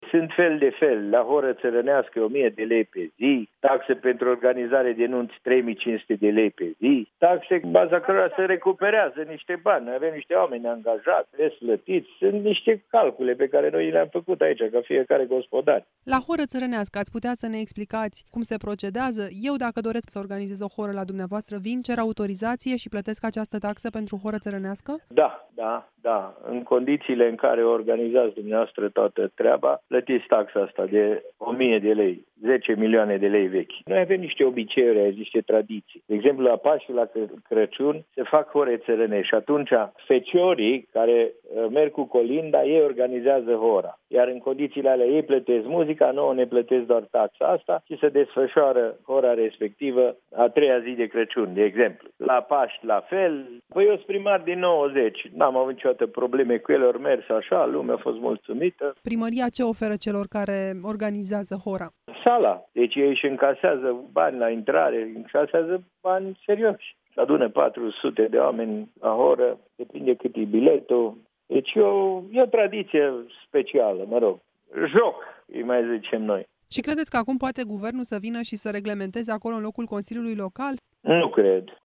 02oct-DCP-interviu-primar-Silviu-Ponorar-taxa-pe-hora-taraneasca.mp3